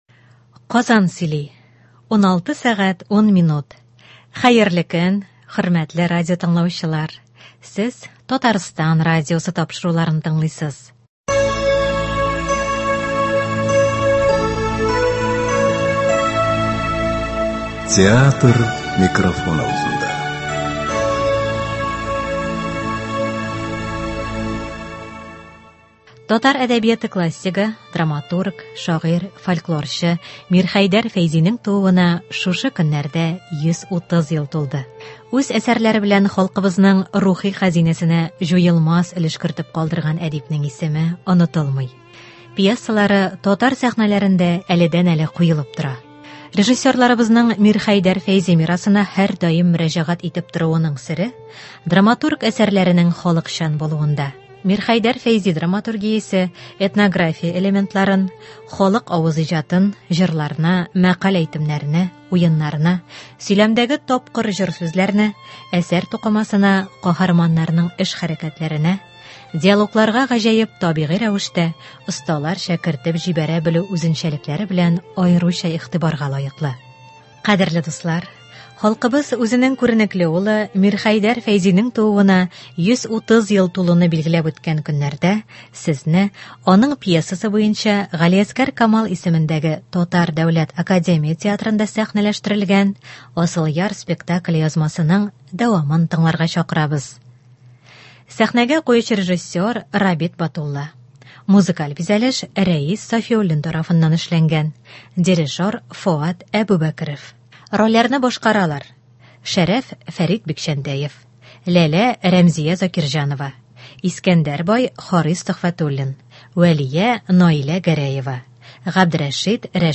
Кадерле дуслар, халкыбыз үзенең күренекле улы – Мирхәйдәр Фәйзинең тууына 130 ел тулуыны билгеләп үткән көннәрдә сезне, аның пьесасы буенча Г.Камал исемендәге Татар Дәүләт академия театрында сәхнәләштерелгән “Асылъяр” спектакле язмасын тыңларга чакырабыз.